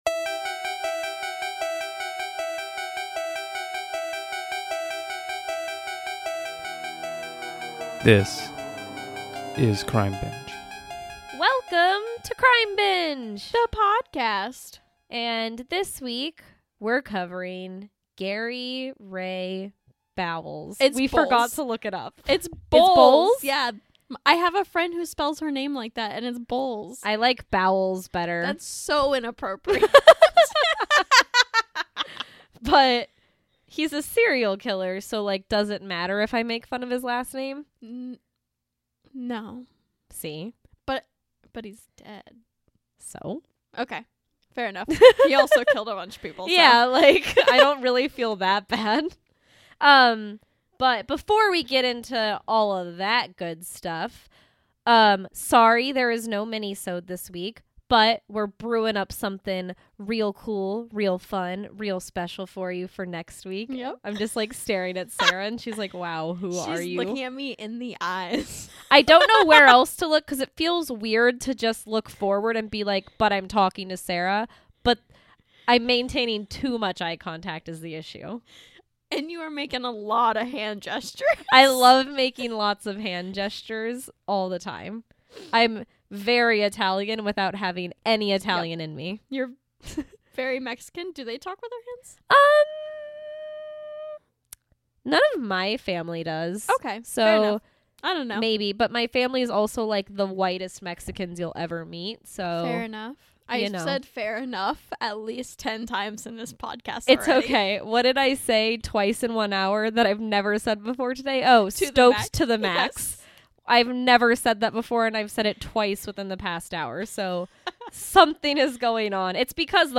This week on Crime Binge the girls talk about the I-95 killer, Gary Ray Bowles. A serial killer who blamed his crimes on gay men.